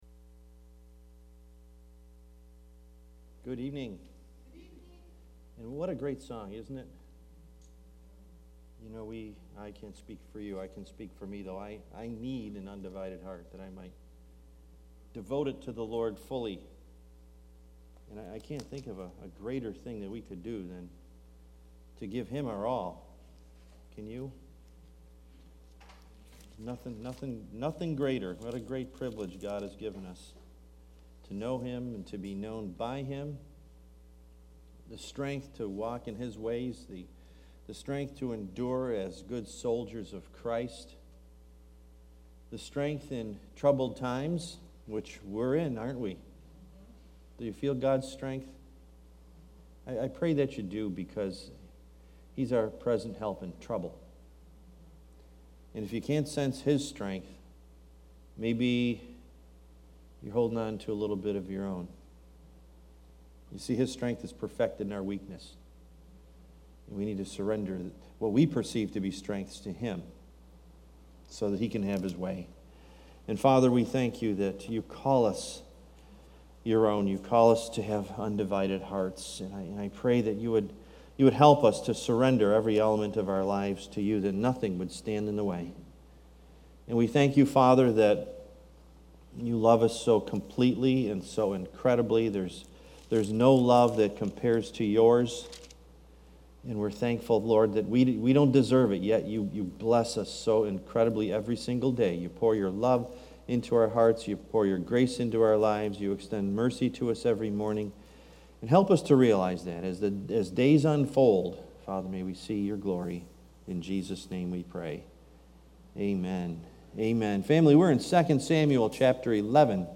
Series: Wednesday Bible Study